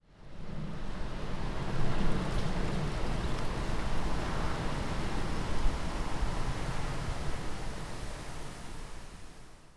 ZK_Framework/Gust 2.wav at 305d1784b5f069fc77a6877e04ba2f7398ddbc7e - ZK_Framework - Gitea: Git with a cup of tea
Gust 2.wav